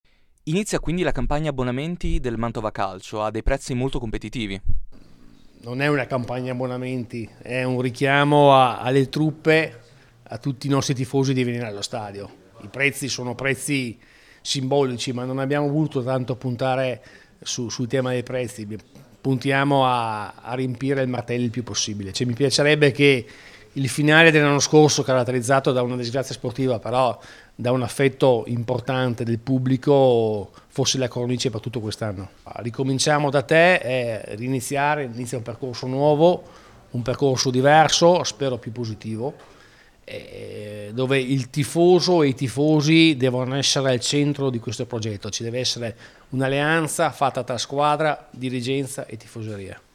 Ecco le dichiarazioni raccolte il giorno della presentazione: